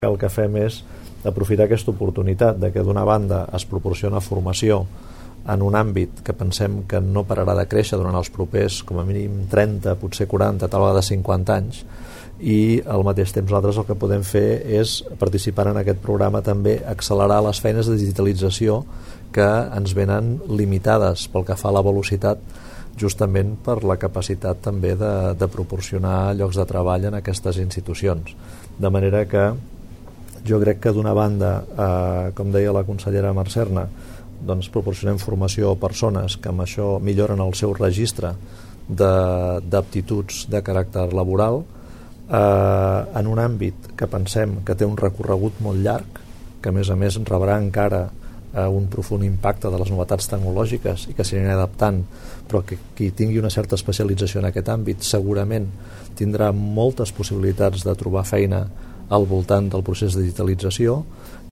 Tall d'àudio: El conseller Tresserras destaca la importància crexient del sector de l'àmbit de la digitalització